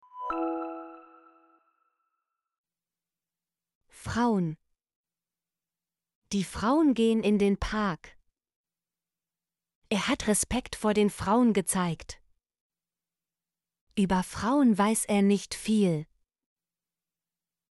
frauen - Example Sentences & Pronunciation, German Frequency List